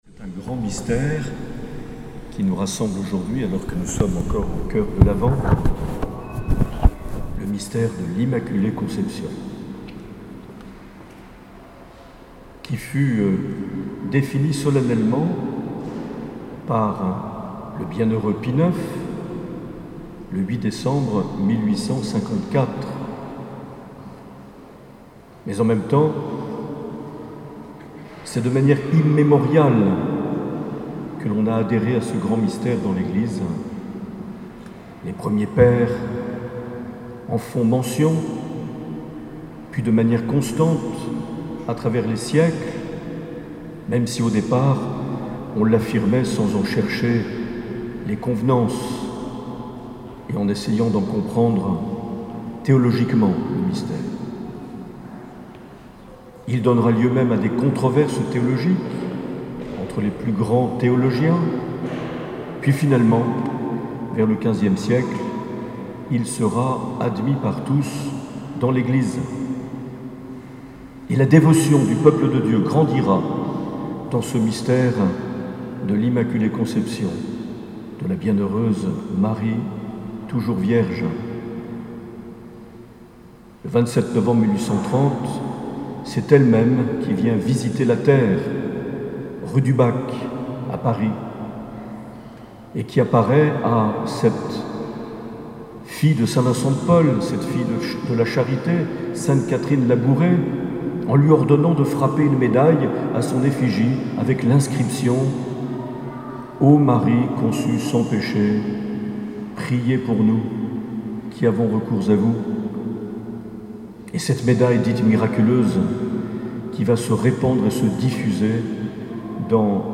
8 décembre 2020 - Cathédrale de Bayonne - Immaculée Conception de la Vierge Marie
Accueil \ Emissions \ Vie de l’Eglise \ Evêque \ Les Homélies \ 8 décembre 2020 - Cathédrale de Bayonne - Immaculée Conception de la Vierge (...)
Une émission présentée par Monseigneur Marc Aillet